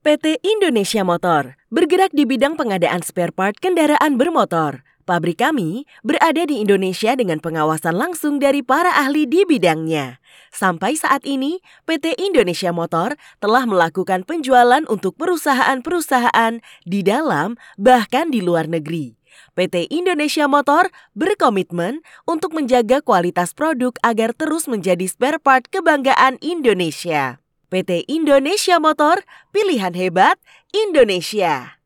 Commercial, Deep, Mature, Warm, Corporate
Corporate